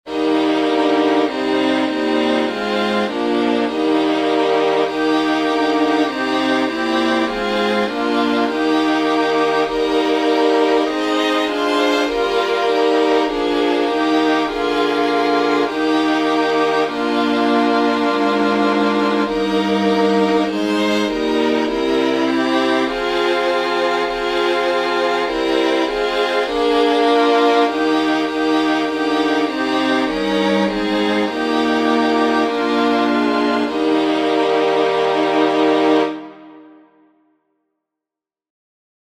Fairest Lord Jesus — in E flat, alternate.
Silesian folk song